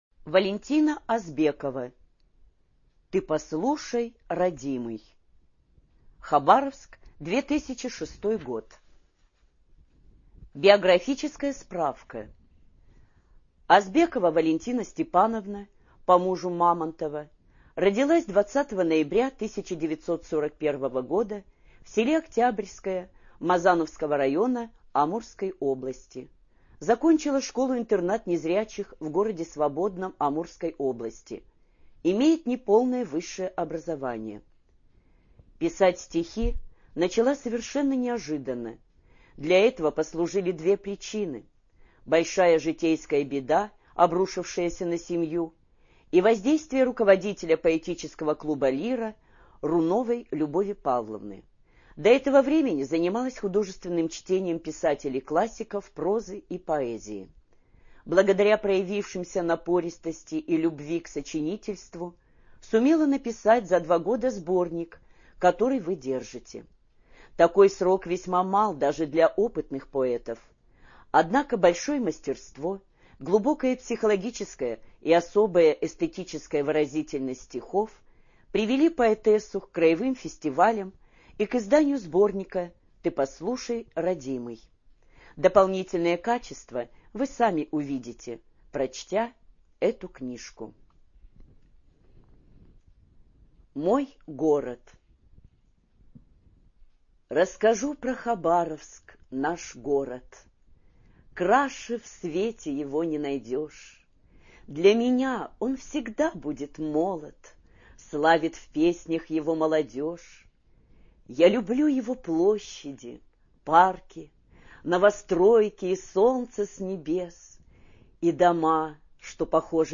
ЖанрПоэзия
Студия звукозаписиХабаровская краевая библиотека для слепых